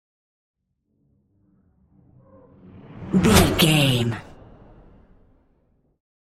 Whoosh super fast
Sound Effects
Fast
futuristic
intense
whoosh